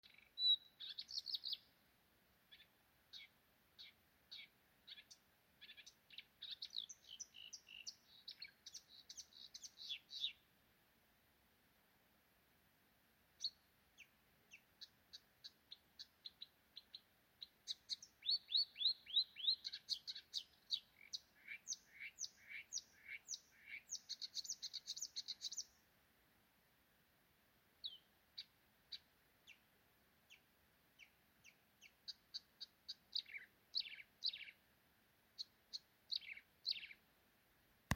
Purva ķauķis, Acrocephalus palustris
StatussDzied ligzdošanai piemērotā biotopā (D)